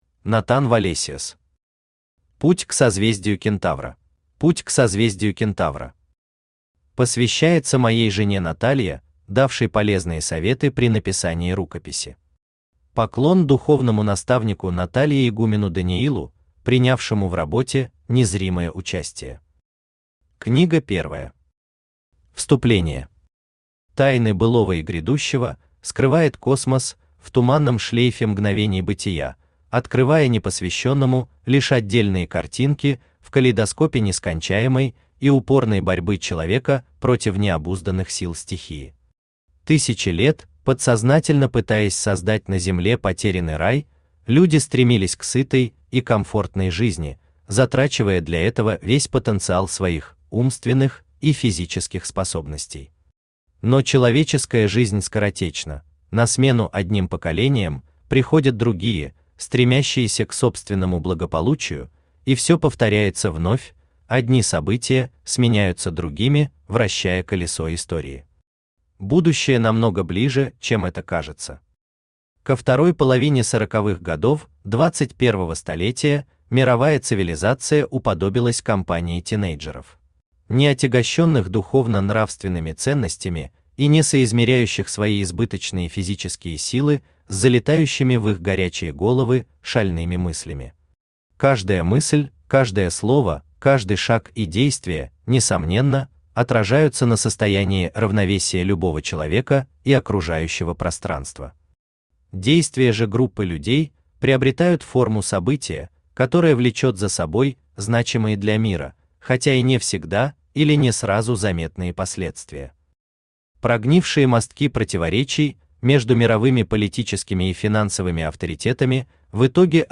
Аудиокнига Путь к созвездию Кентавра | Библиотека аудиокниг
Aудиокнига Путь к созвездию Кентавра Автор Натан Валесиос Читает аудиокнигу Авточтец ЛитРес.